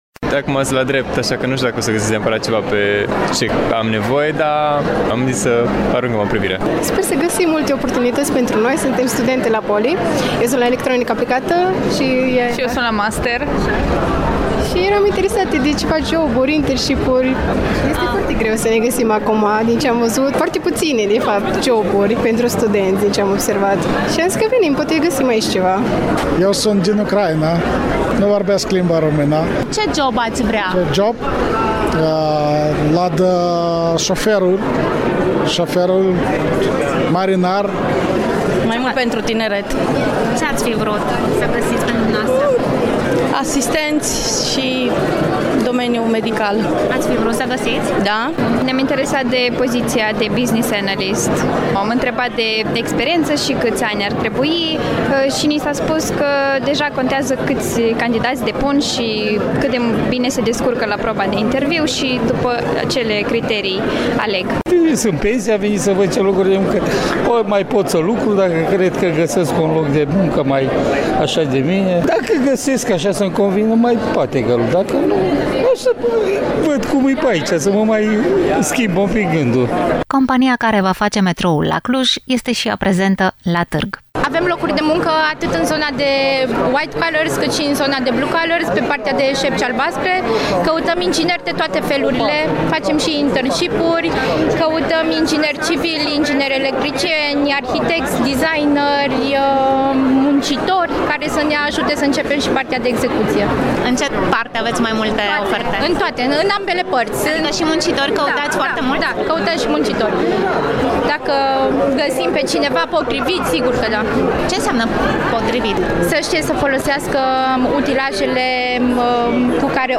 Pulsul Târgului de Cariere, de la participanți | FOTO